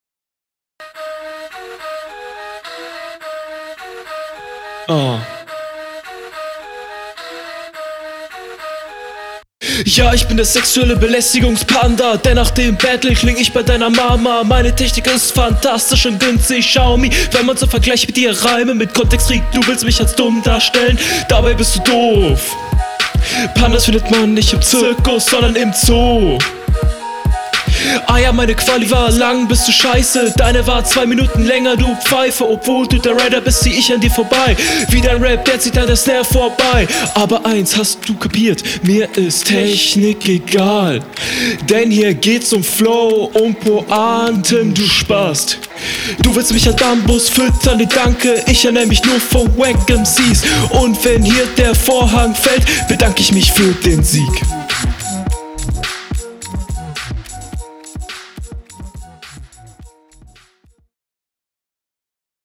Flow sitzt besser im Takt als beim Gegner.
Stimme ist okay, Abmische auch okay.